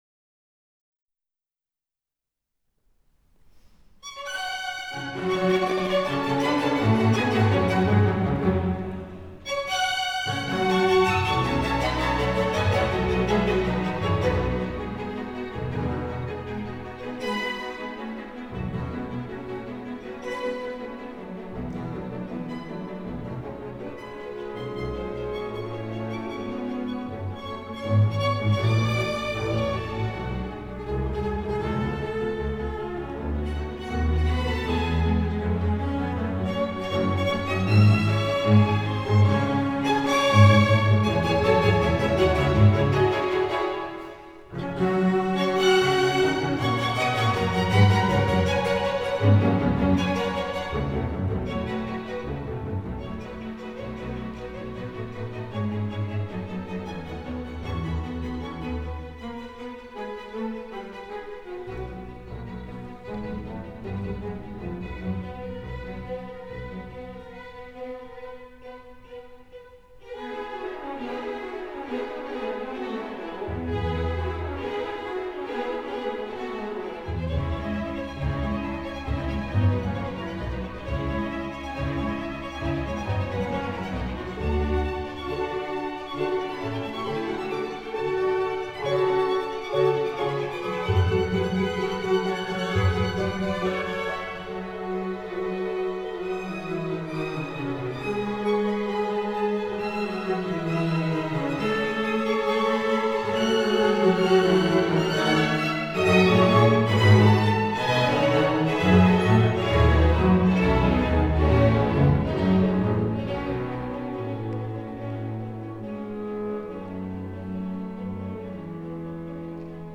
アザレア室内合奏団演奏会
過去の演奏のご紹介